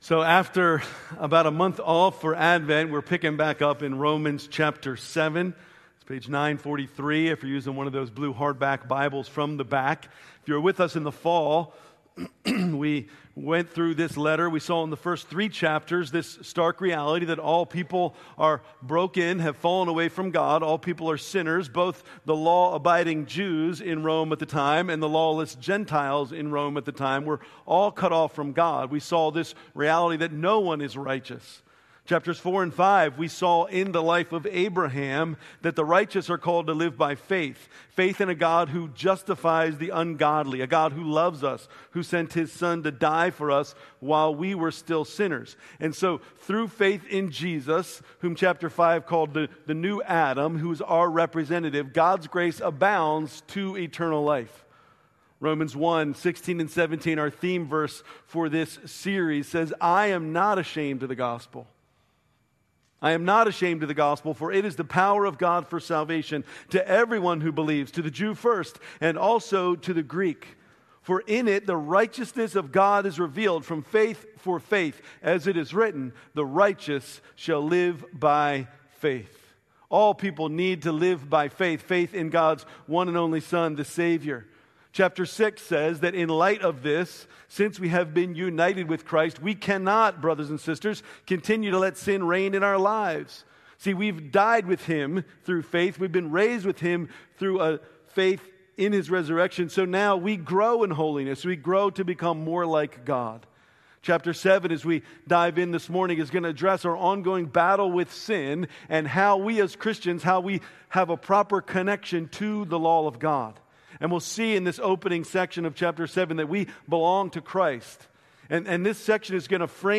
January, 2026 Worship Service Order of Service: